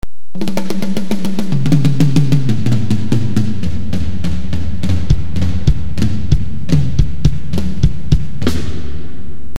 Mp3 Surround Effects by Mp3 Arena
Drums 299Kb  0:10 256 Sur Drums Everywhere!
drums.mp3